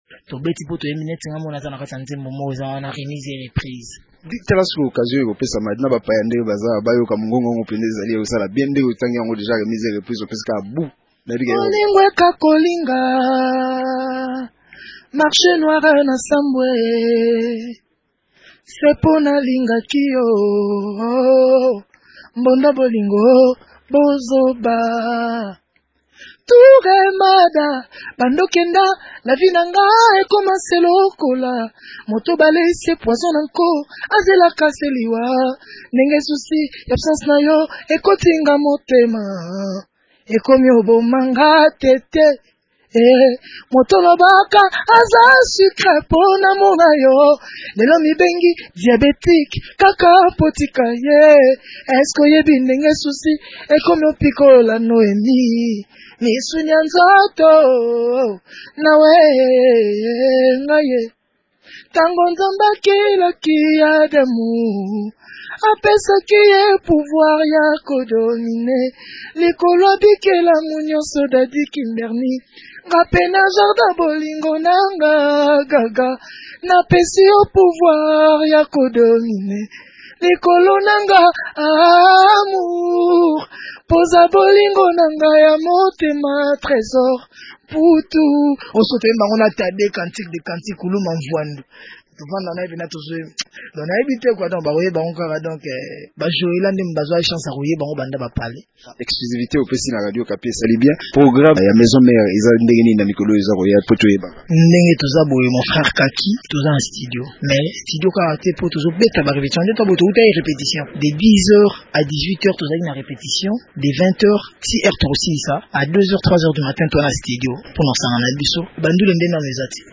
Réponse dans cet entretien